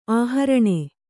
♪ āharaṇe